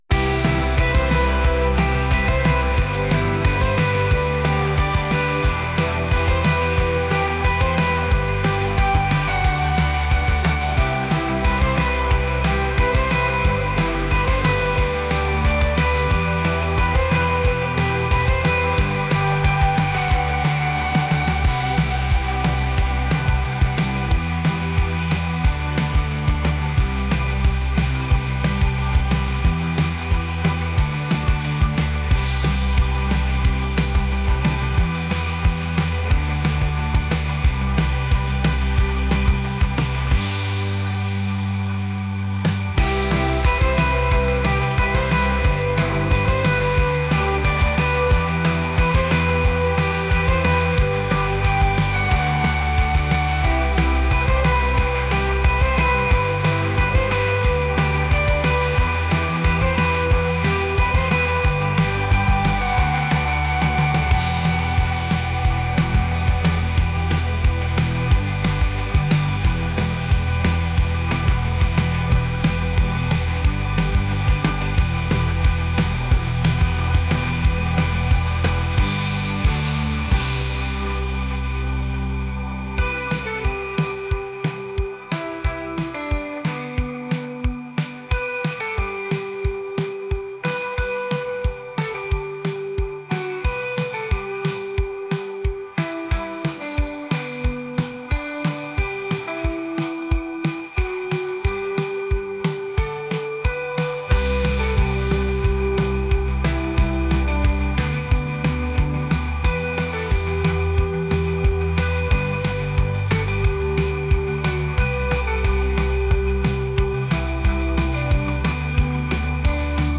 indie | rock | alternative